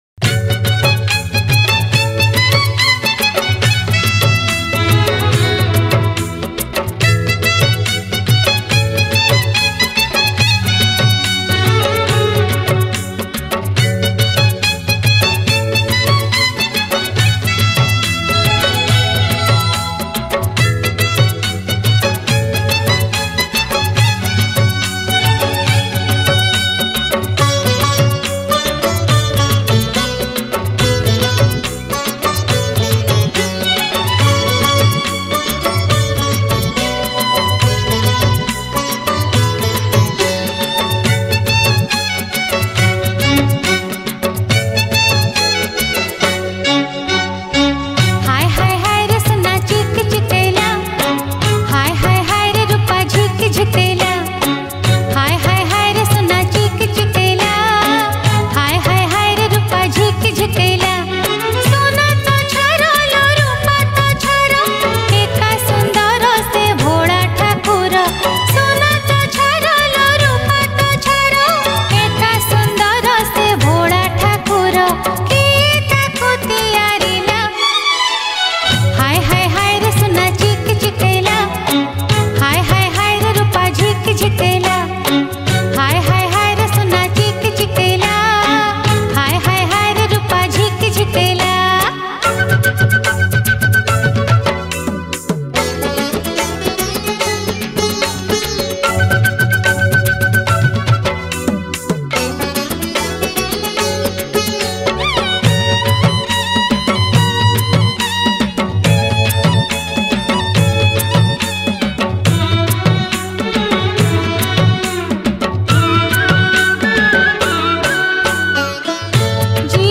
Jagara Special Odia Bhajan Song